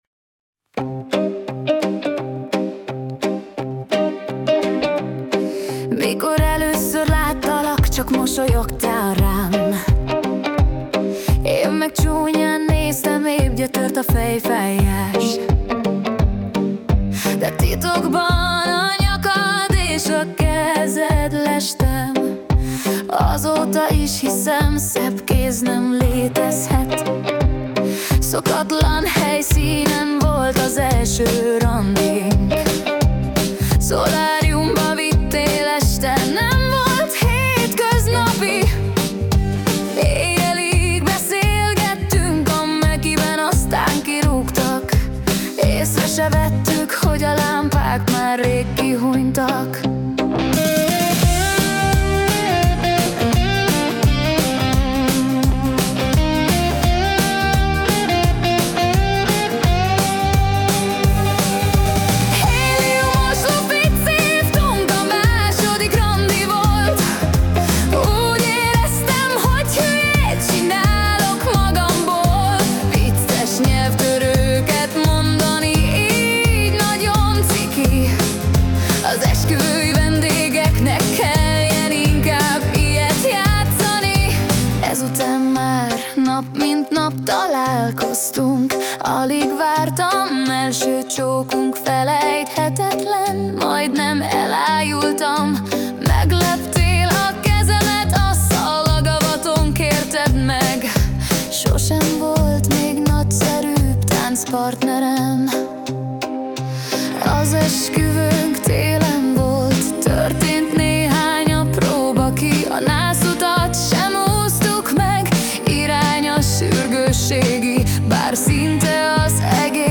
Pop - Évfordulóra
Személyre szabott ajándék dal - Alkalom: évforduló